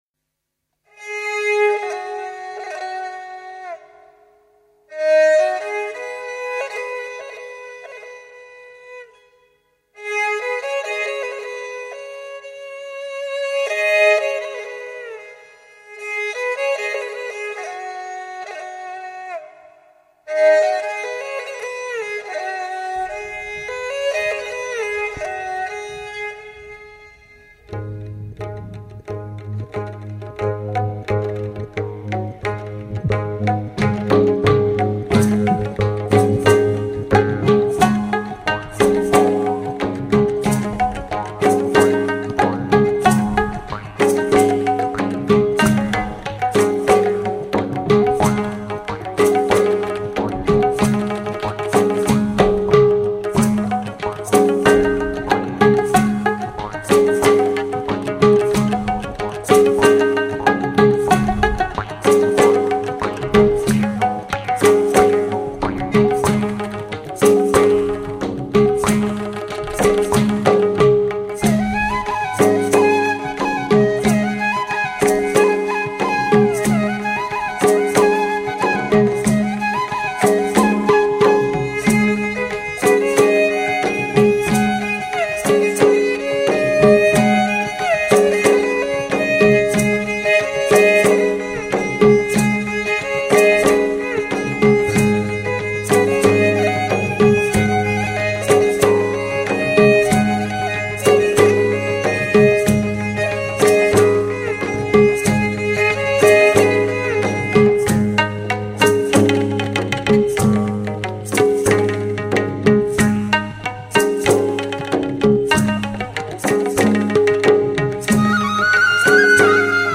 南亚音乐风格。